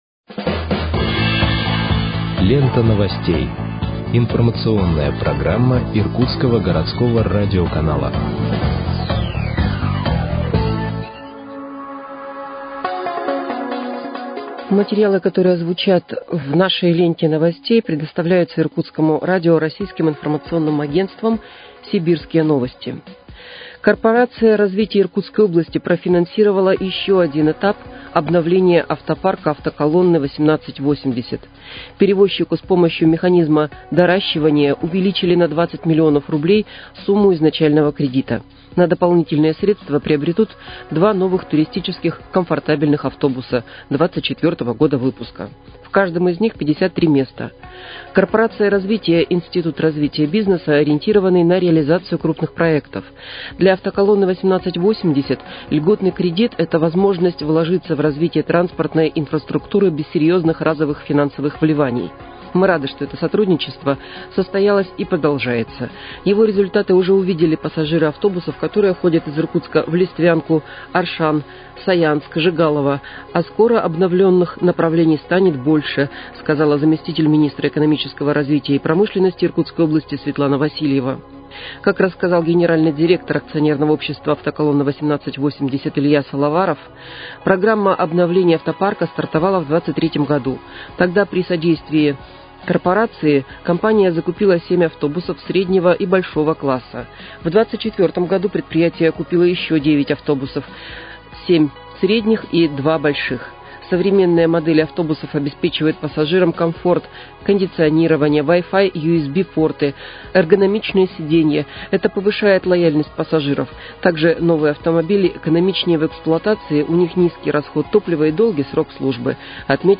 Выпуск новостей в подкастах газеты «Иркутск» от 14.05.2025 № 1